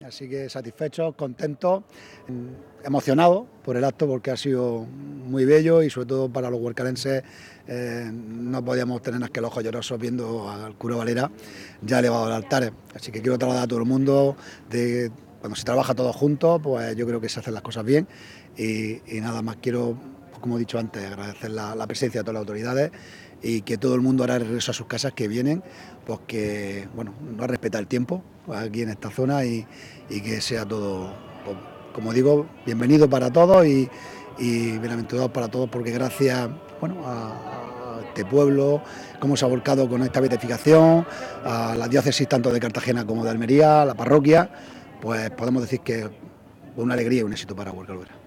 Domingo-Fdz-Alcalde-HO.mp3